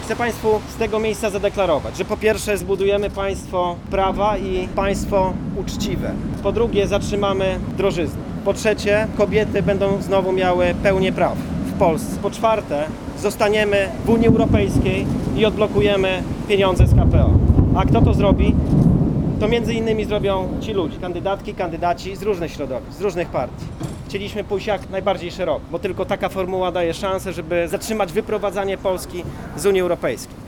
Politycy Koalicji Obywatelskiej rozpoczęli zbiórkę podpisów Poseł Dariusz Joński przedstawił postulaty w których poruszył sprawę bezpieczeństwa i finansów publicznych.